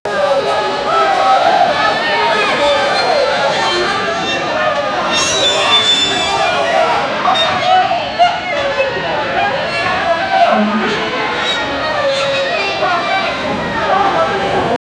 Brawl Sound Effects - Free AI Generator & Downloads
noisy-brawl-in-a-saloon-w6xstu2n.wav